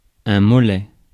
Ääntäminen
Synonyymit gras de la jambe Ääntäminen France: IPA: /mɔ.lɛ/ Haettu sana löytyi näillä lähdekielillä: ranska Käännös 1. прасец {m} Suku: m .